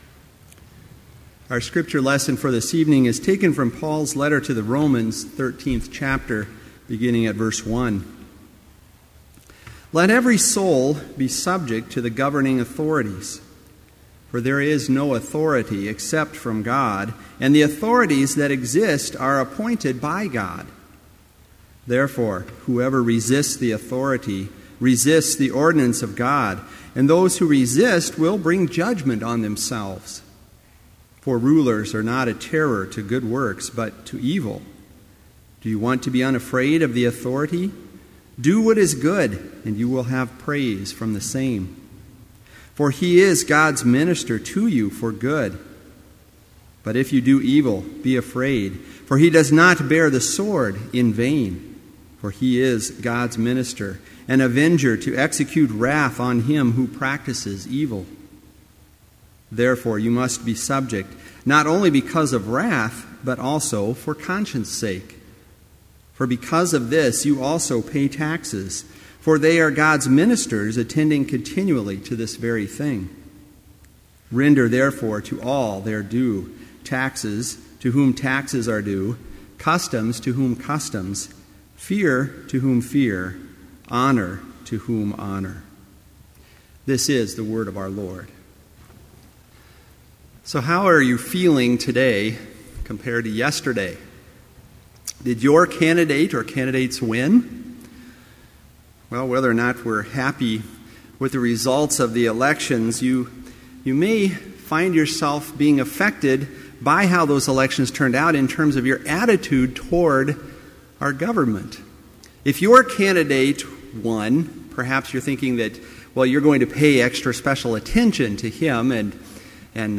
Vespers worship service in BLC's Trinity Chapel
Complete service audio for Evening Vespers - November 7, 2012